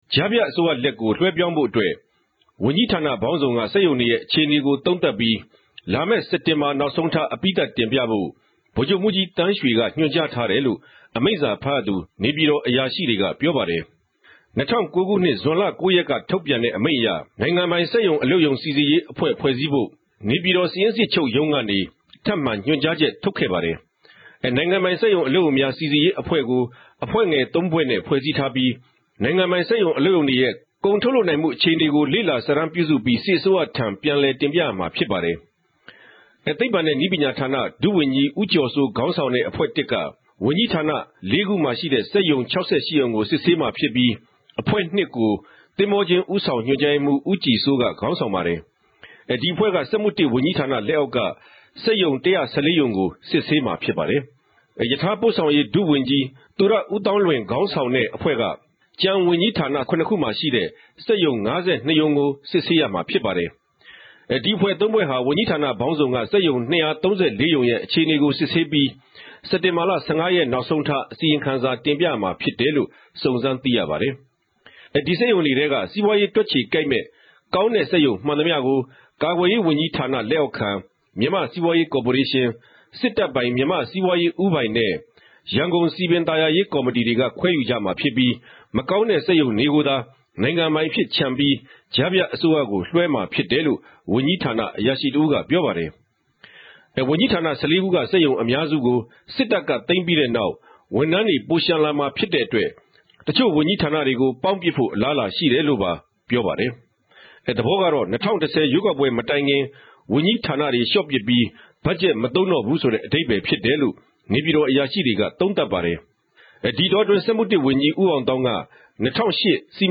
သုံးသပ်တင်ူပခဵက်။